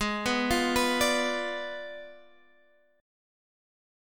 Bdim/Ab chord